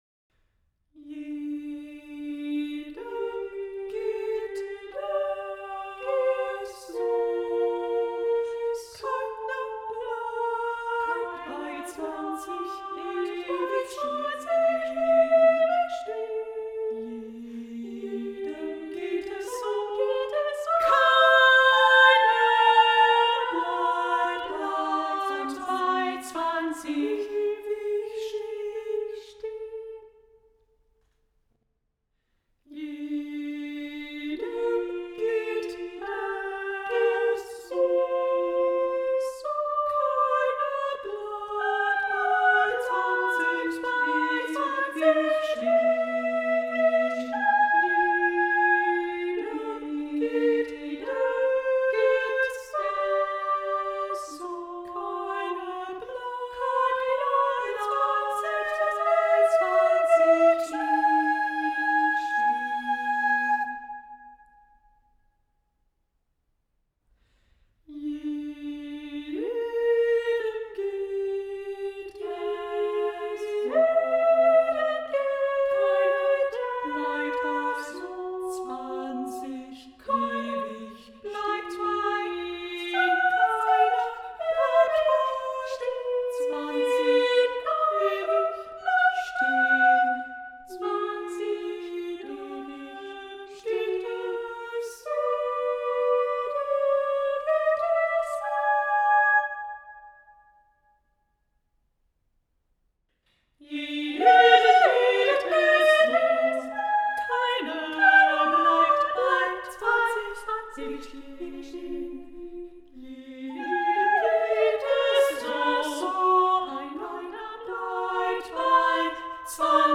Sopranos
Mezzos
Tenors
Basses